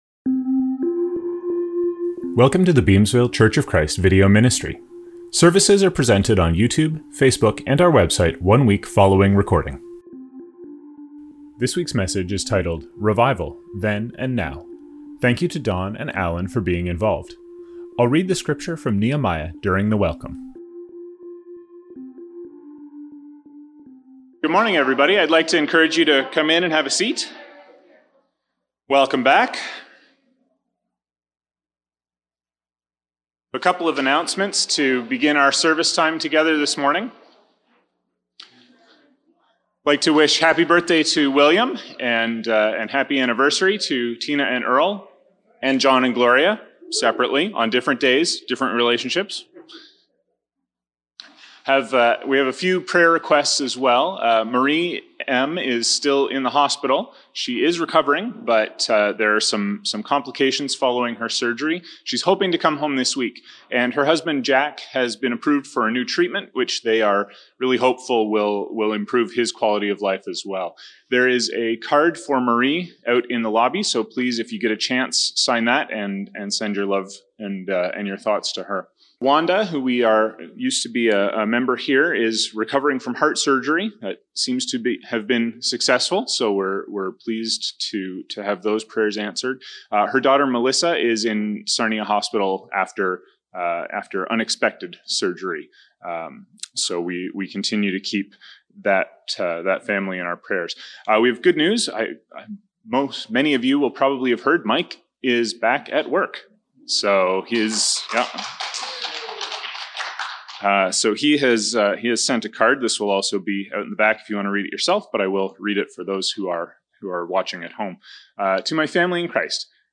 Scriptures from this service include: Welcome - Nehemiah 8:5-6; 8:9-12.